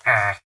Sound / Minecraft / mob / villager / no2.ogg